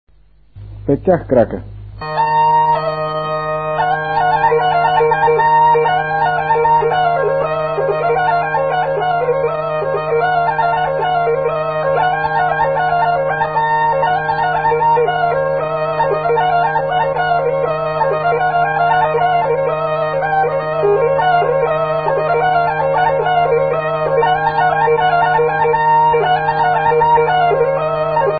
музикална класификация Инструментал
тематика Хороводна (инструментал)
размер Четири осми
фактура Двугласна
начин на изпълнение Солово изпълнение на гайда
фолклорна област Североизточна България
начин на записване Магнетофонна лента